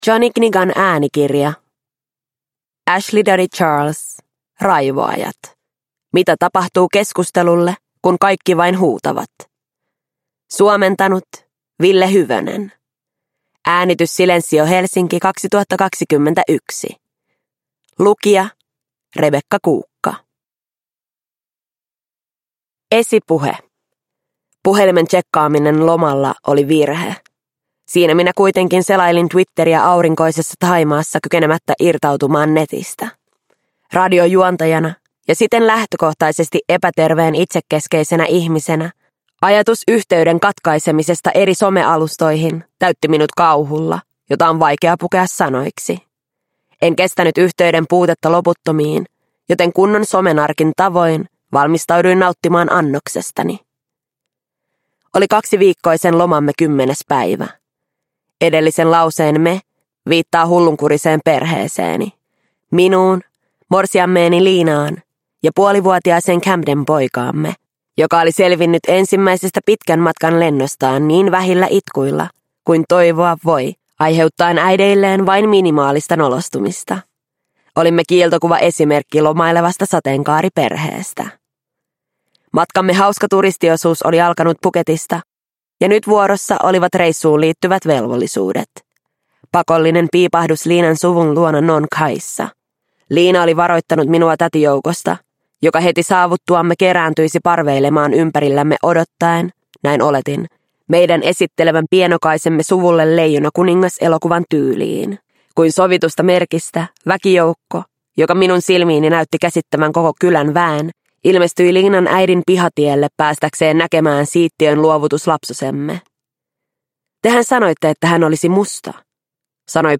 Raivoajat – Ljudbok – Laddas ner